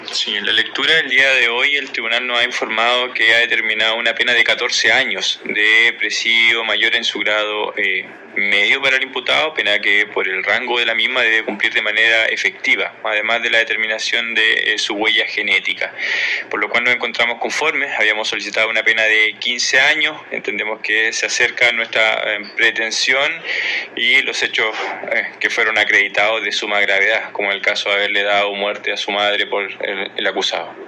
CUÑA-FISCAL-LEONEL-IBACACHE.mp3